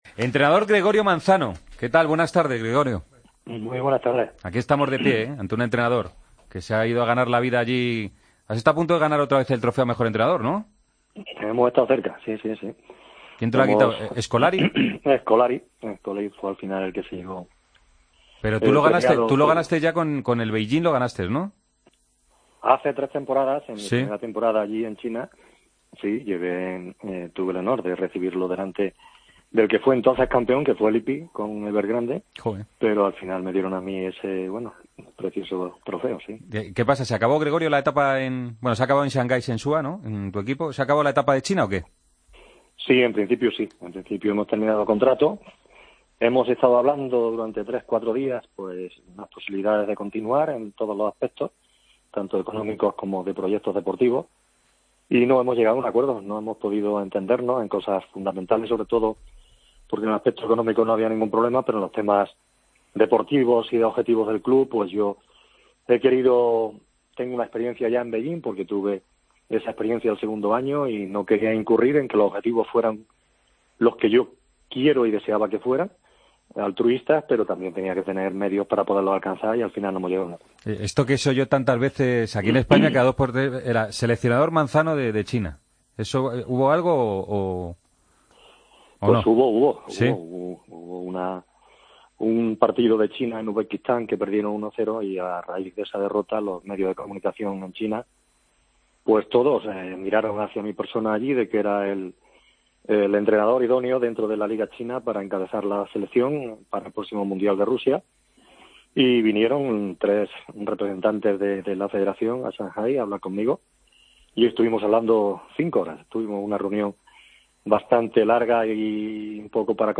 El entrenador pasa por Deportes COPE tras su marcha de China. Manzano confiesa que pudo ser el seleccionador del país asiático, habla de cómo se ve la Liga Española allí y cuenta una anécdota de Diego Costa.